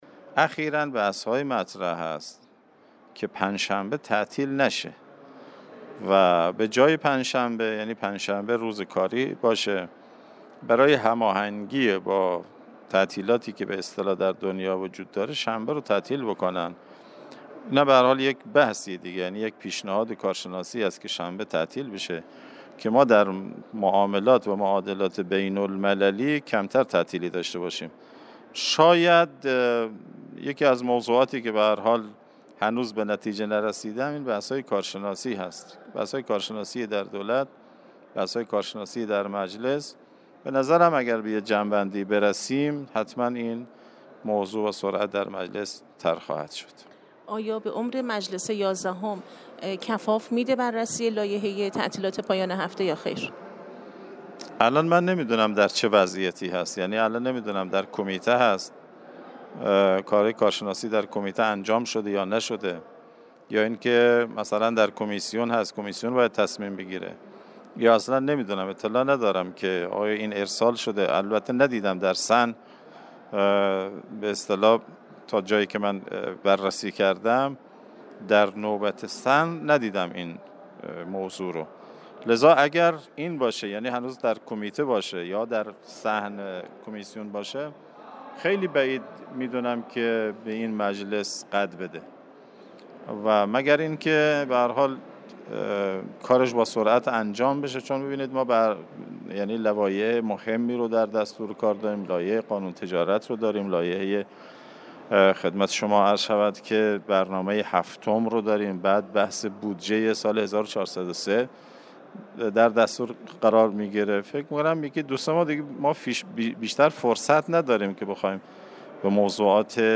موسی غضنفرآبادی رئیس کمیسیون قضایی حقوقی مجلس در گفتگو با فردای اقتصاد به این سوالات پاسخ داد.